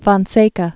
(fôn-sākə, fōn-sĕkä), Gulf of